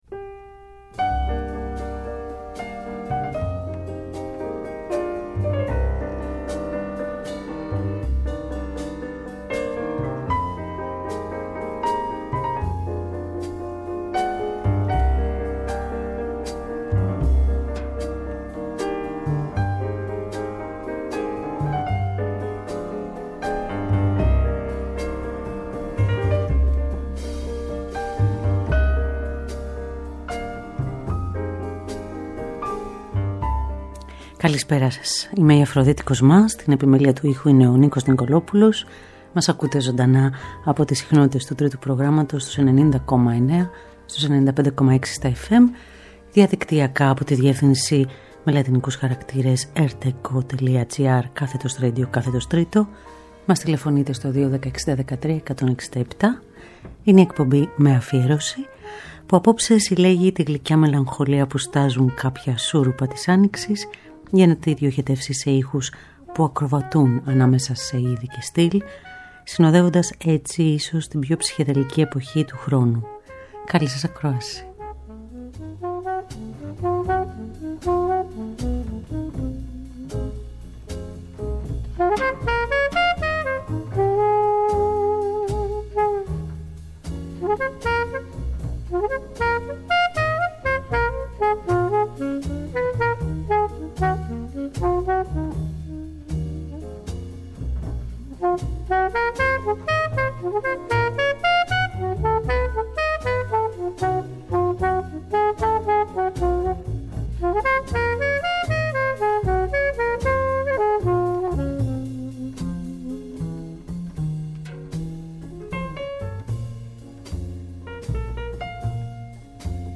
Συλλέγουμε τη μελαγχολία που στάζουν κάποια σούρουπα της Άνοιξης για να τη διοχετεύσουμε σε ήχους πoυ ακροβατούν, συνοδεύοντας έτσι ίσως την πιο ψυχεδελική εποχή του χρόνου.
Ζωντανά από το στούντιο του Τρίτου Προγράμματος.